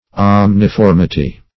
Search Result for " omniformity" : The Collaborative International Dictionary of English v.0.48: Omniformity \Om`ni*for"mi*ty\, n. The condition or quality of having every form.
omniformity.mp3